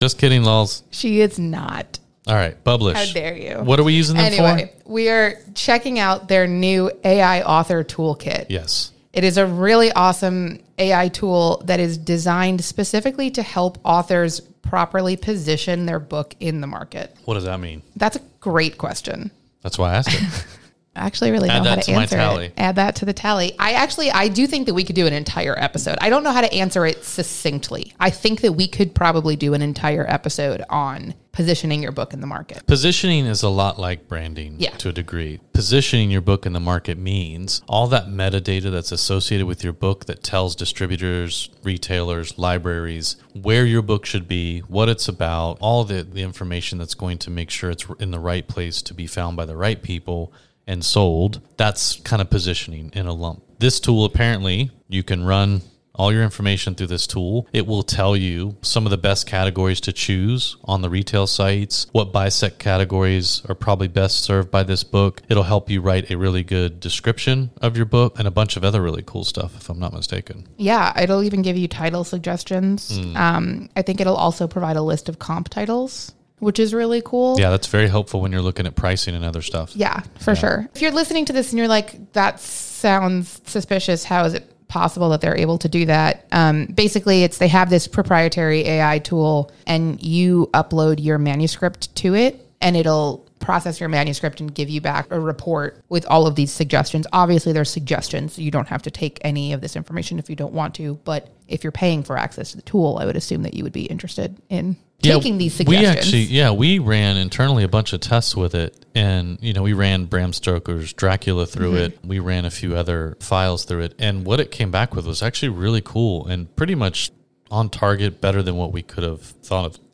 Lulu-Podcast-Sound-Clip-About-Bublish-AI-Tool.mp3